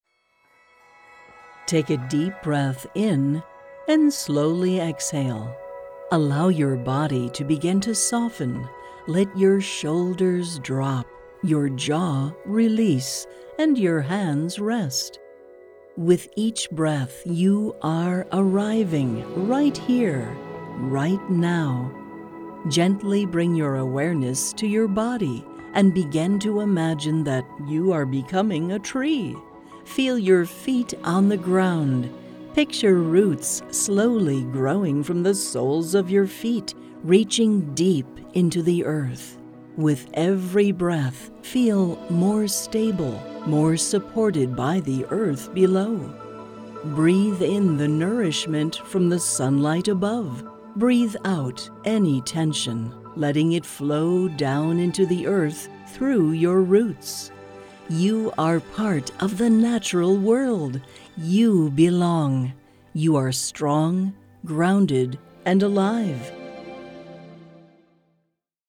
Meditation Demo
Tree-Meditation-Mixdown-8.10.25.mp3